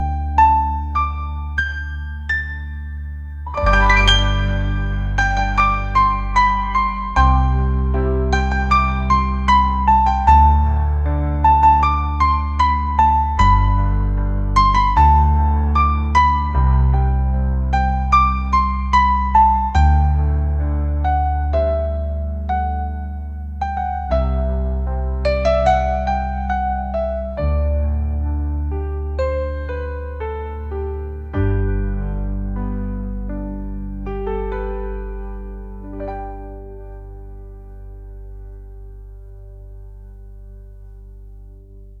soul & rnb | pop